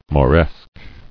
[Mo·resque]